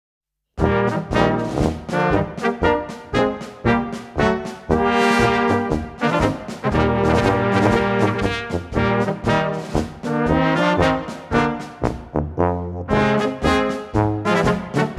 Gattung: Polka für kleine Besetzung
Besetzung: Kleine Blasmusik-Besetzung